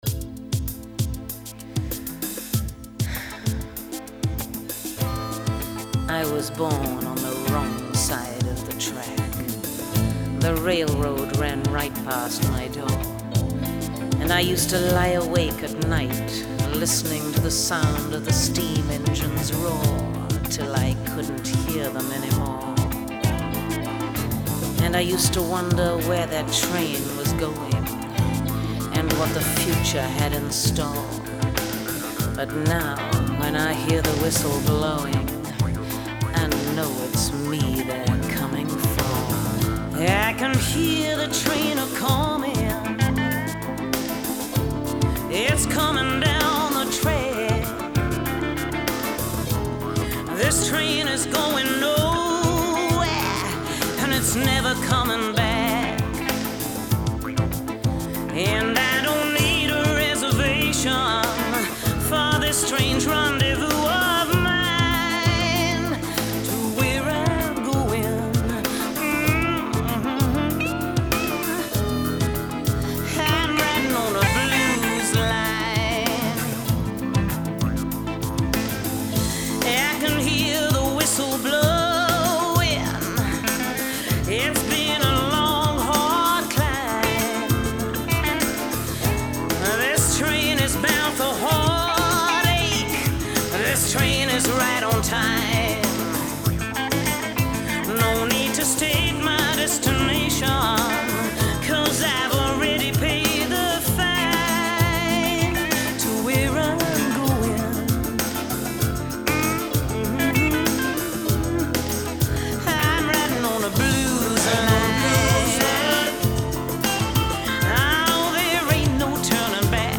Блюзы и блюзики